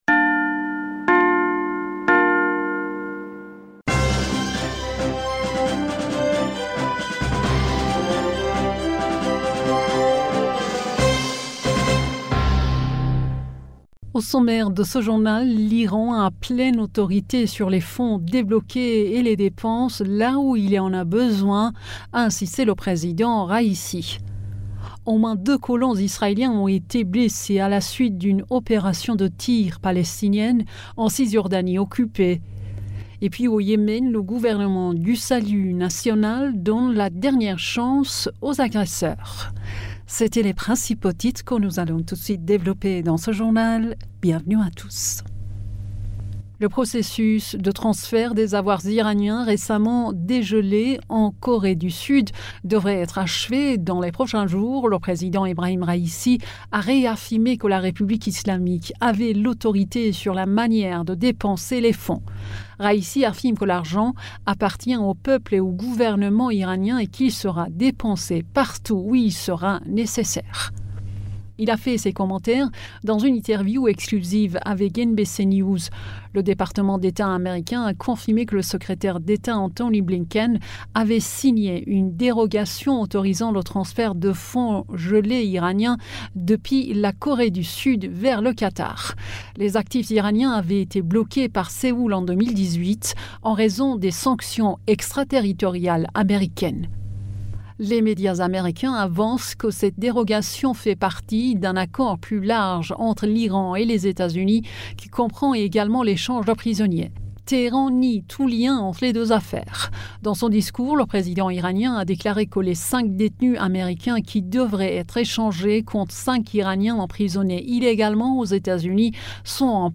Bulletin d'information du 13 Septembre 2023